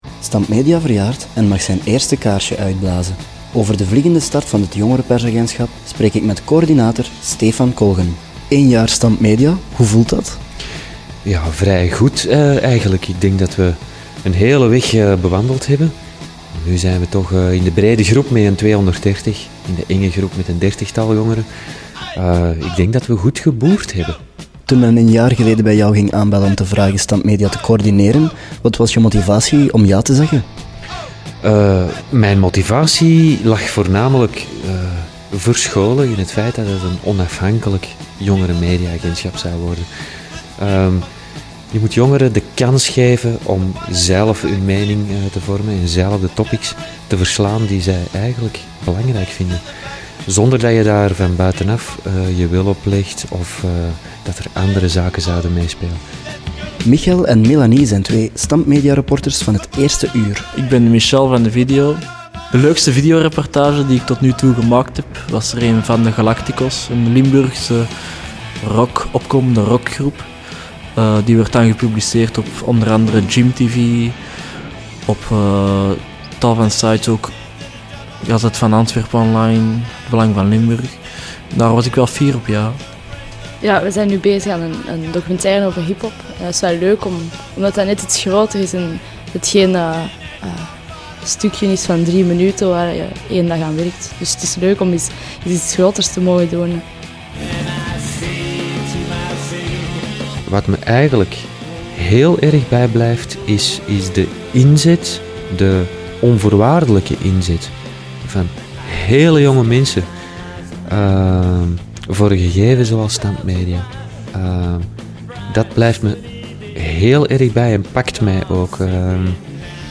korte audio-reportage